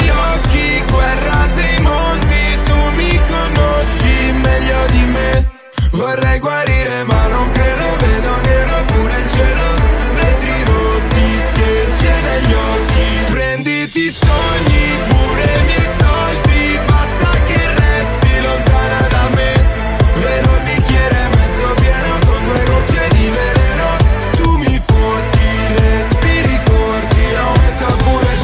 Genere: italiana,sanremo2025,pop.ballads,rap,hit